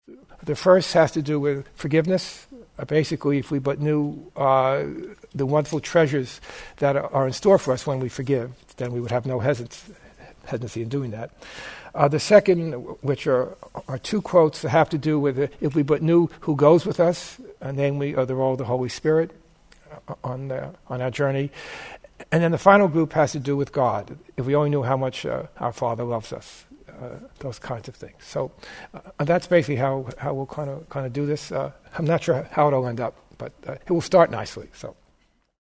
Throughout A Course in Miracles, Jesus promises us the wonderful future that awaits us when we follow his gentle guidance: If you but knew…. The workshop is divided into three sections that reflect the categories of these statements.